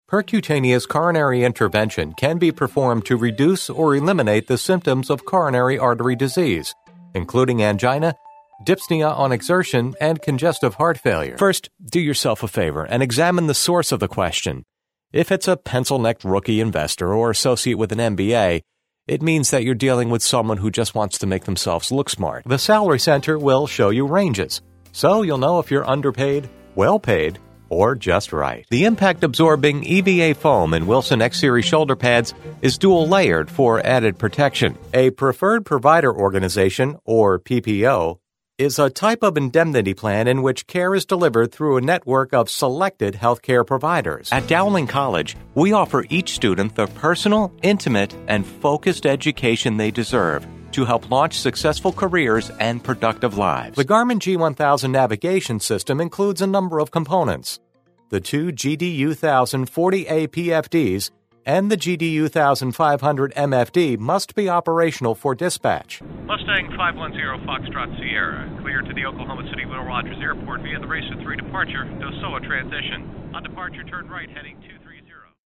Energetic-Professional-Articulate-Sincere-Authoritative-Friendly-Familiar-Trustworthy-Inviting- Smooth-Crisp-Dynamic-Informative-Clear-Educational-Corporate-Intelligent.
mid-atlantic
Sprechprobe: eLearning (Muttersprache):
Private ISDN Studio.